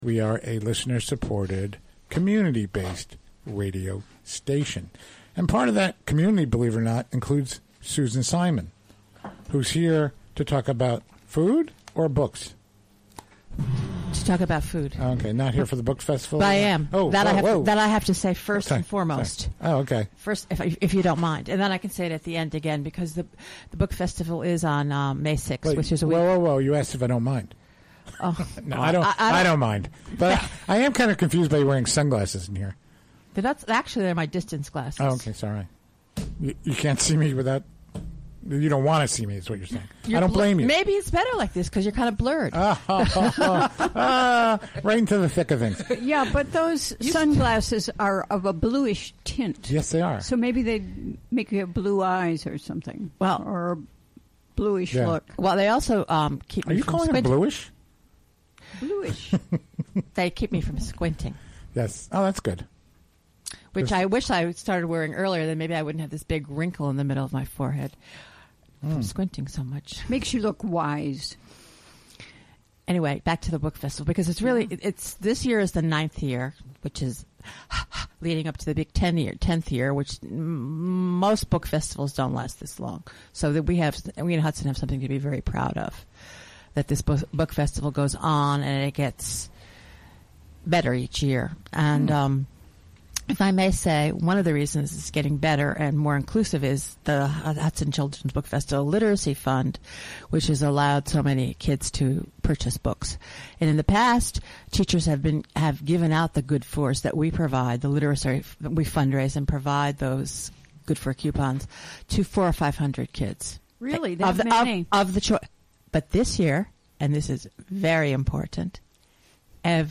Recorded live during the WGXC Afternoon Show Thursday, Apr. 27, 2017.